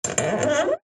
Металлический рычаг тянет скрип 2